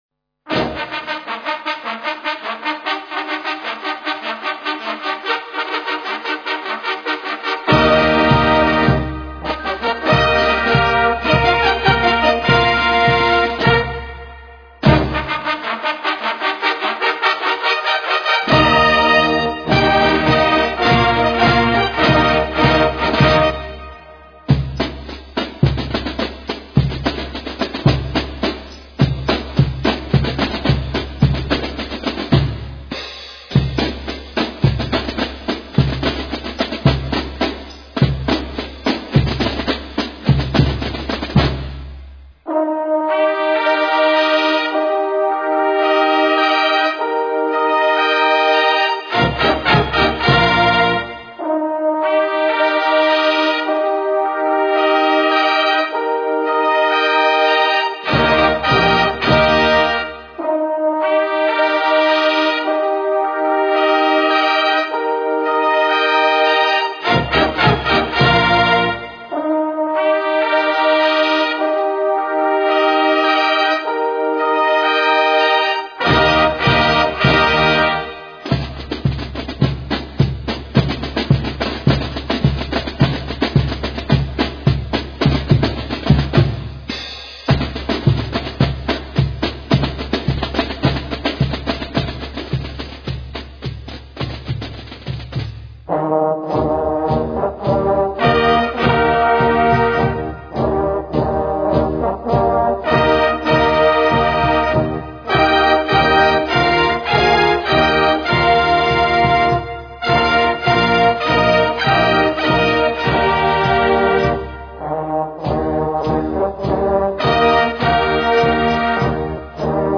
Besetzung: Naturtonfarenzug.
Musik für Naturton-Fanfarenzüge
Hörbeispiel in trad. Perc.-Besetzung